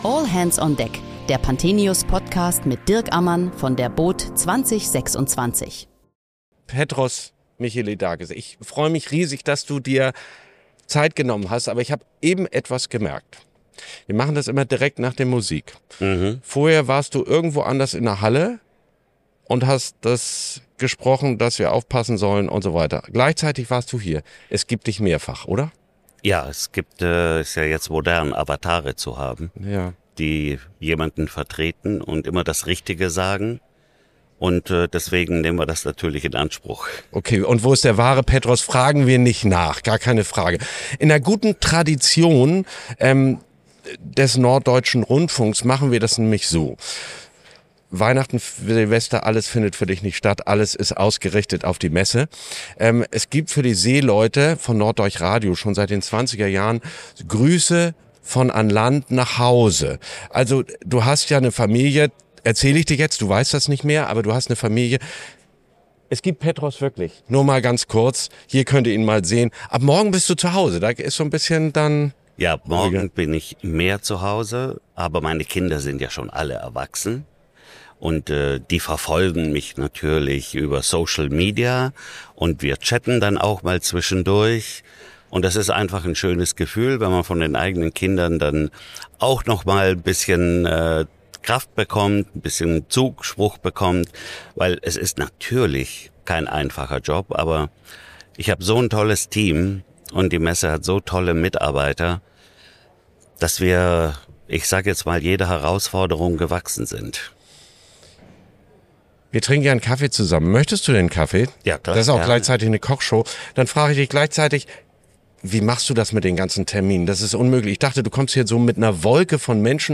Während der boot Düsseldorf 2026 sprechen wir täglich mit Gästen aus der Branche über aktuelle und kontroverse Themen rund um den Wassersport.